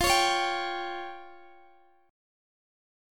Listen to Fsus2#5 strummed